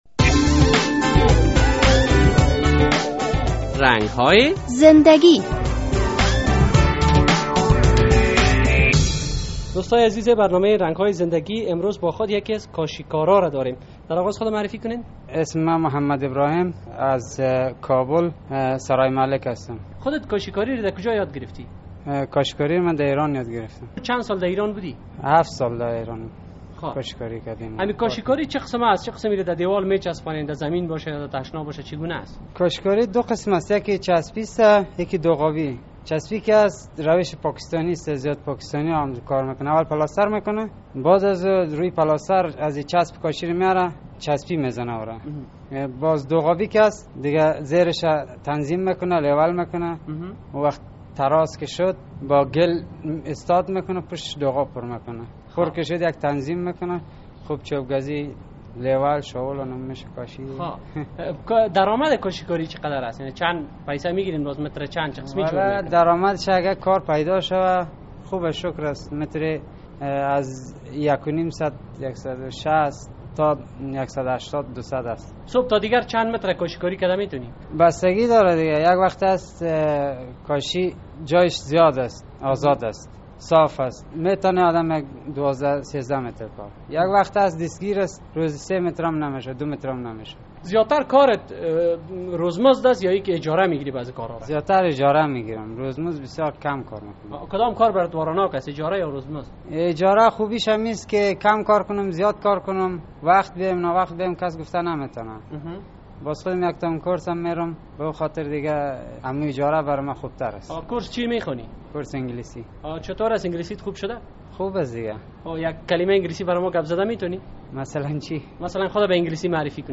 در این برنامهء رنگ های زندگی خبرنگار رادیو آزادی با یک کاشی کار صحبت کرده است.